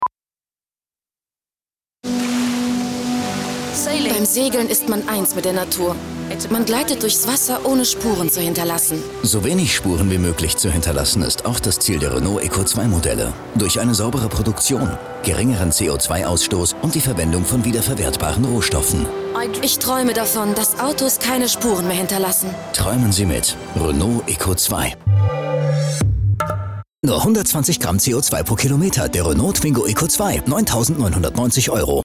Sprechprobe: Sonstiges (Muttersprache):
german female voice over talent.